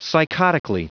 Prononciation du mot psychotically en anglais (fichier audio)
Prononciation du mot : psychotically